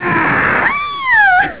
Tia screams!
scream.au